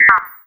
sci-fi_code_fail_02.wav